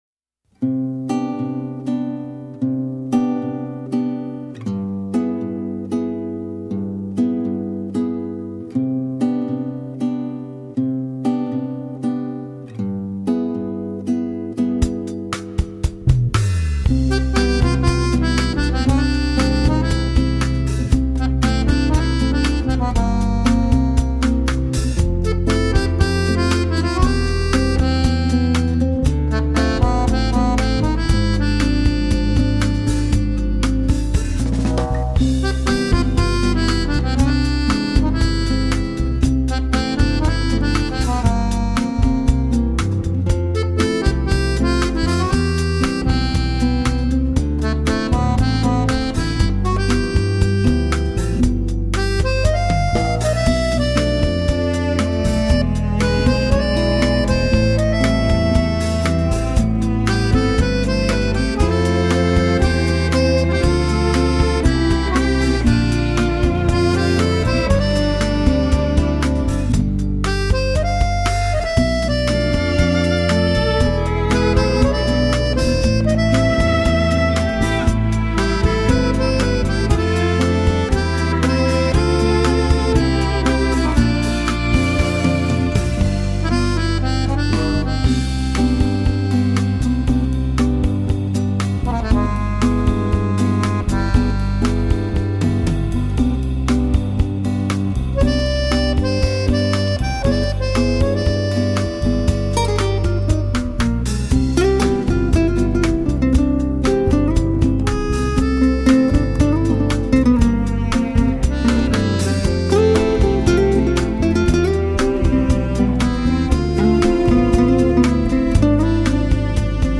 集合多位古典、跨界、流行演奏家携手合演，
偶而你会听见乐手的按键声、呼吸声，或是因为表情，速度变得忽快忽慢。